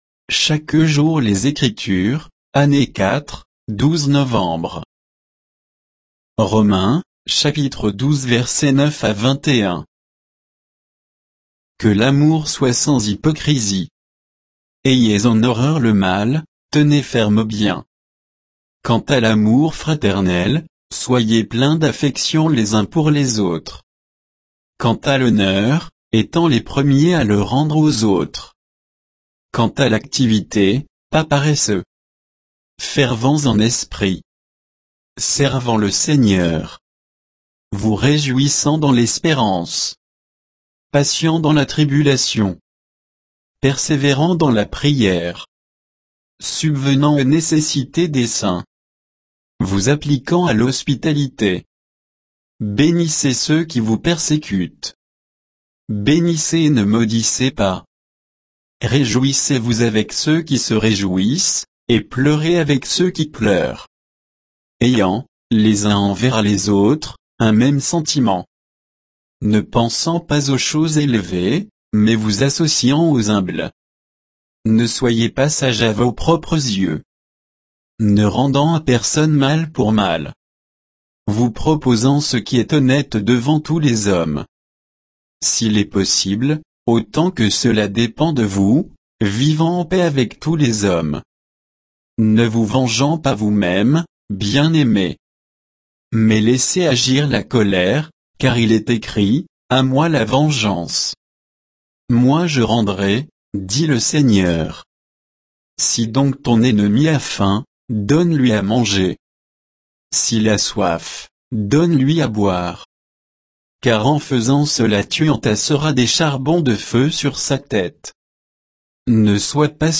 Méditation quoditienne de Chaque jour les Écritures sur Romains 12